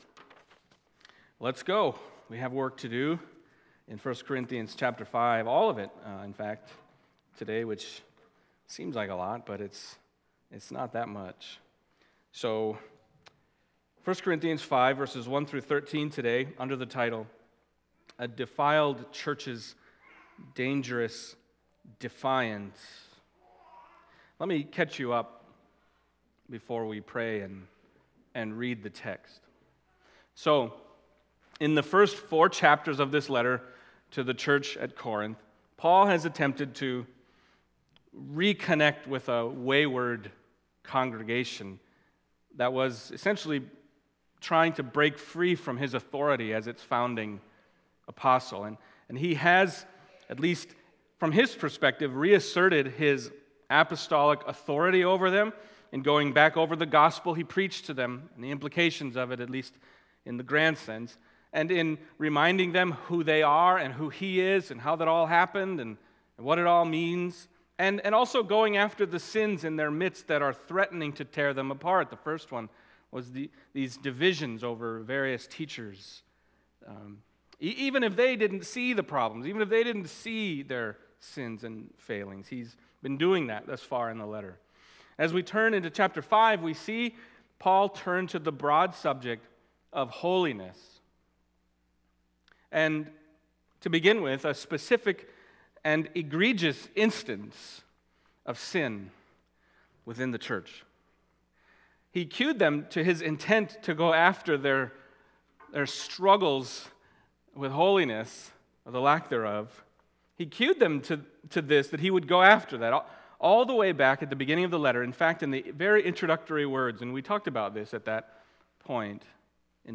Passage: 1 Corinthians 5:1-13 Service Type: Sunday Morning